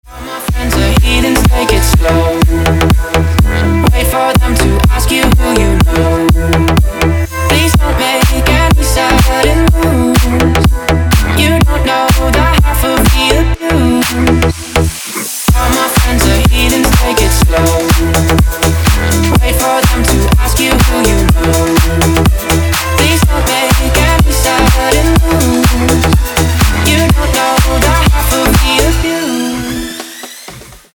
• Качество: 256, Stereo
мужской вокал
Club House
electro house
vocal